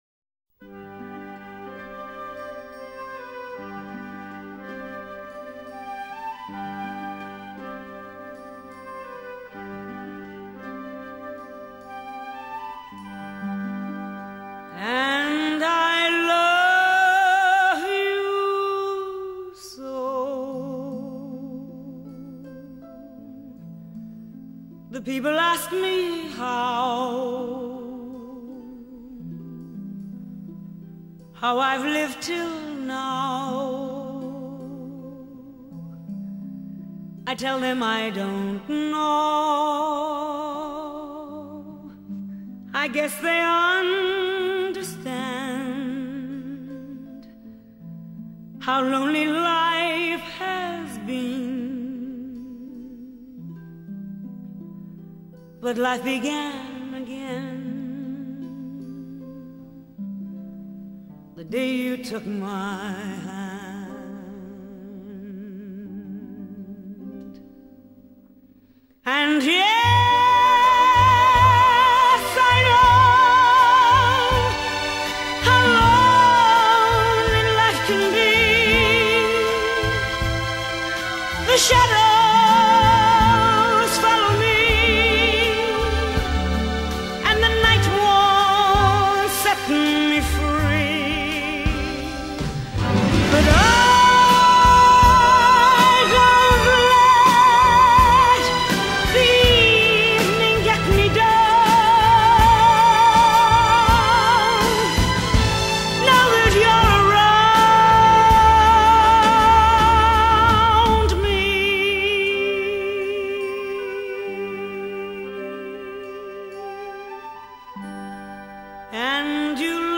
Solo Recorder